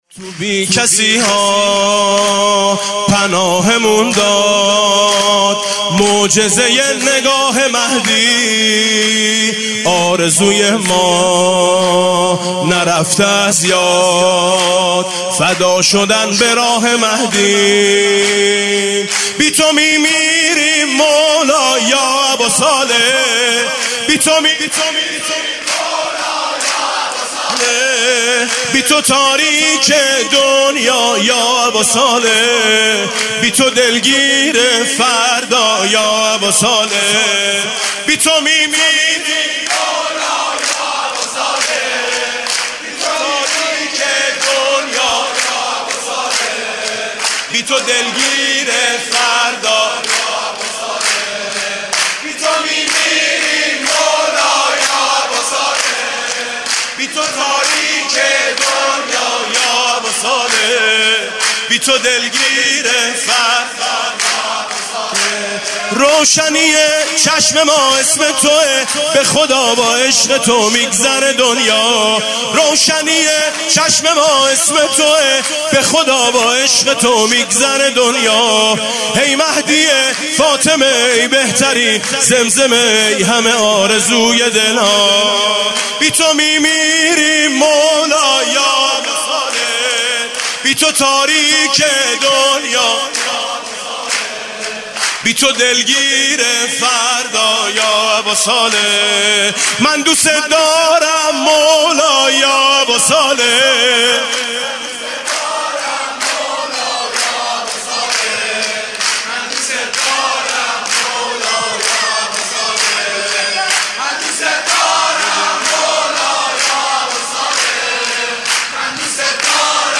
1 اردیبهشت 98 - حرم امامزاده قاضی الصابر - سرود - اگه بیای همیشه عیده
ولادت امام زمان (عج)